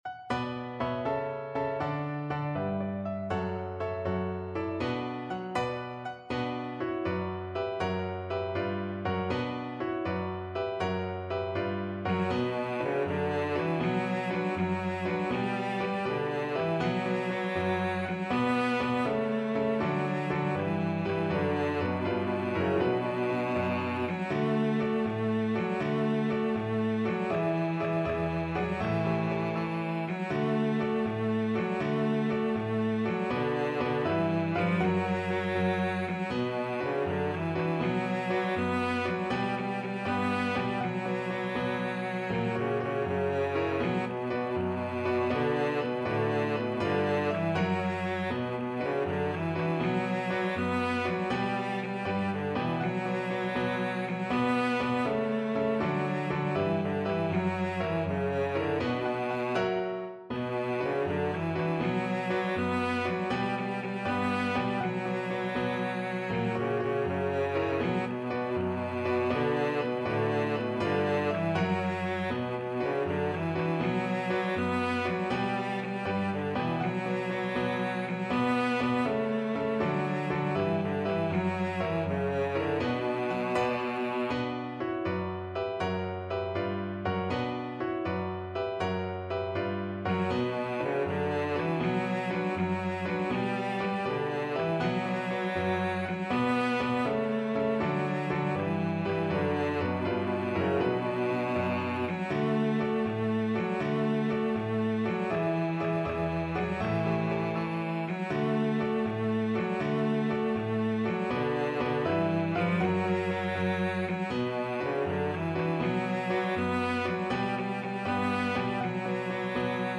Free Sheet music for Cello
Cello
B minor (Sounding Pitch) (View more B minor Music for Cello )
Brightly, but not too fast
6/8 (View more 6/8 Music)
B3-B4
Classical (View more Classical Cello Music)